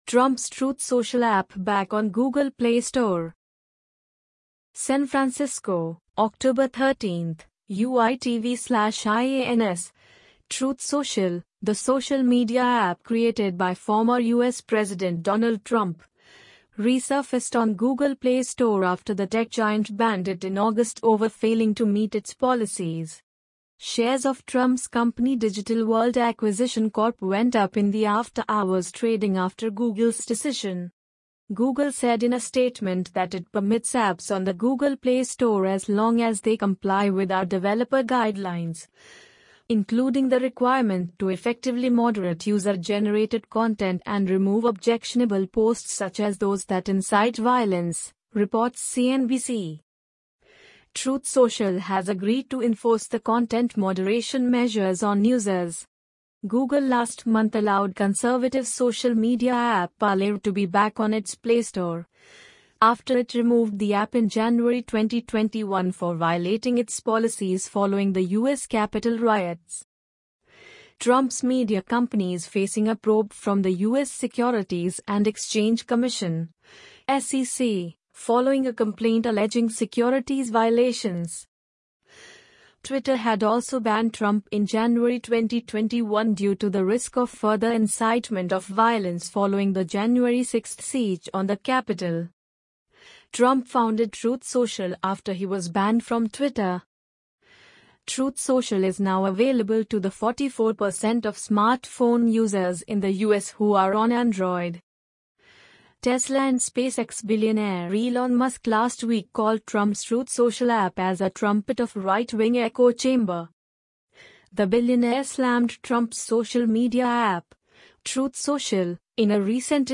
amazon_polly_11680.mp3